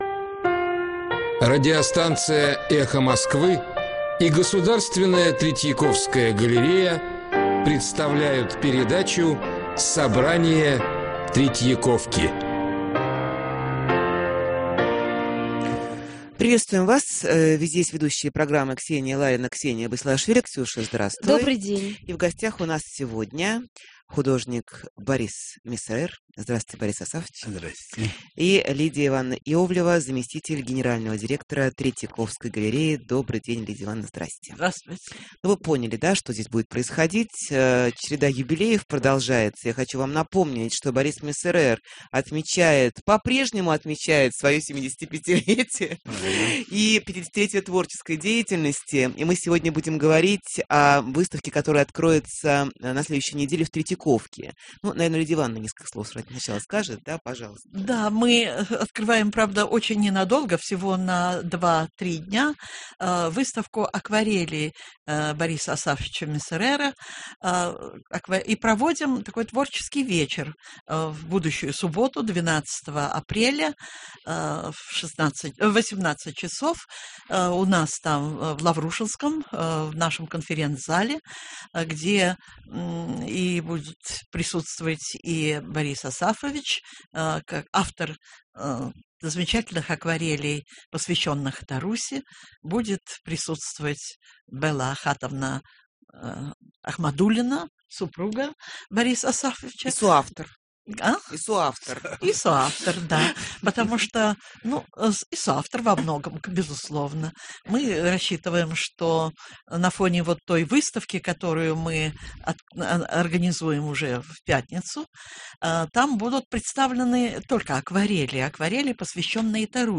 К.ЛАРИНА: И в гостях у нас сегодня художник Борис Мессерер.